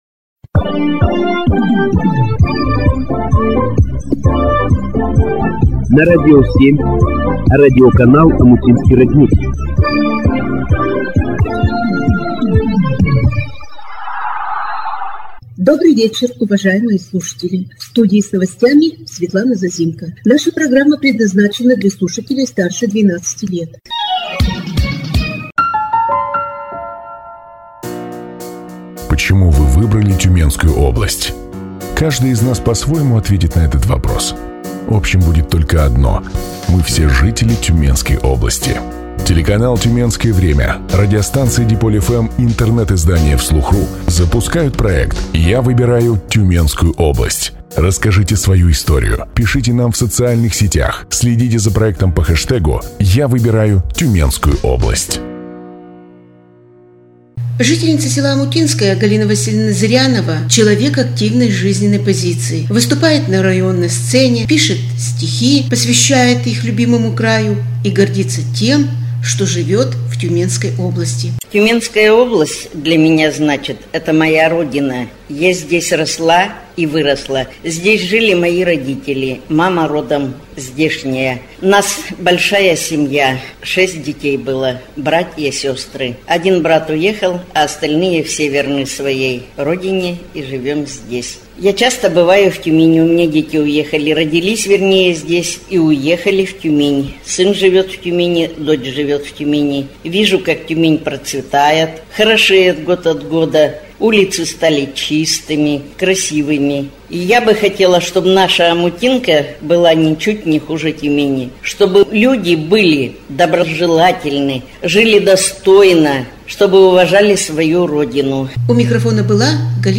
Эфир радиоканала "Омутинский родник" от 23 августа 2016 года